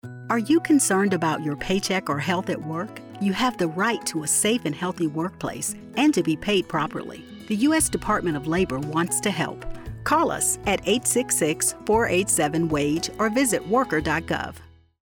Farm Worker Health Public Service Announcement